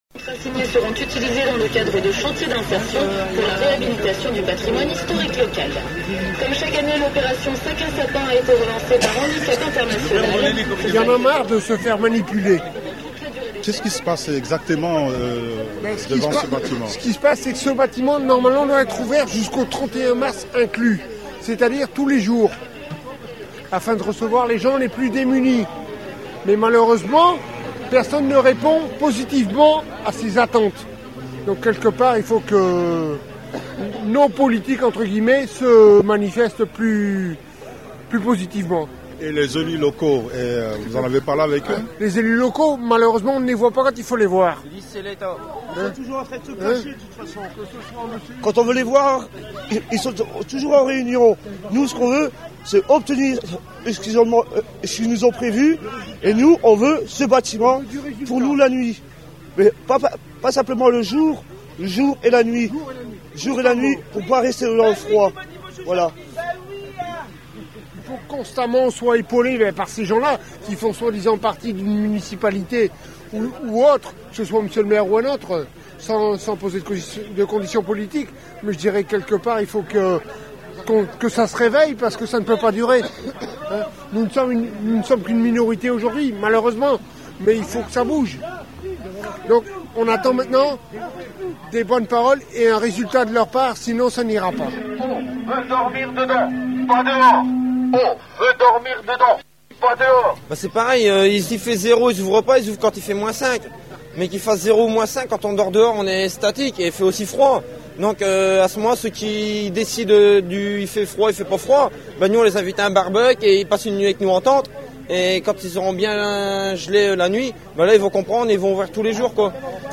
Un reportage sur les sans abri de Dunkerque